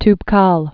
(tb-käl)